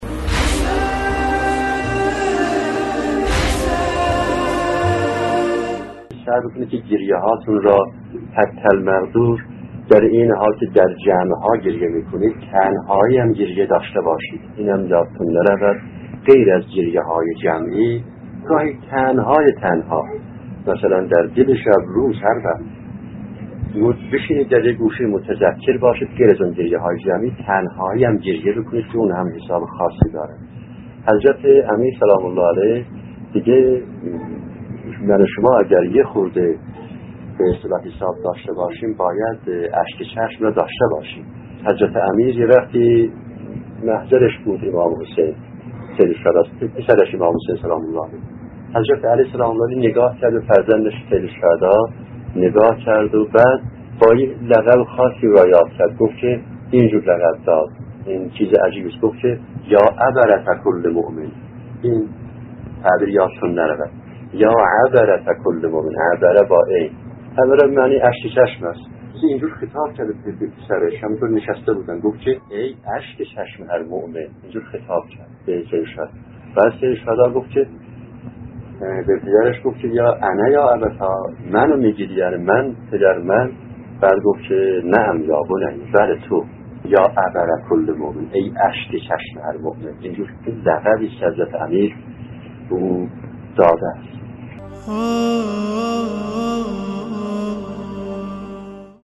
در ادامه، قسمت بیست‌وچهارم این سلسله‌گفتار را با عنوان «اشکِ چشمِ هر مؤمن» می‌شنوید.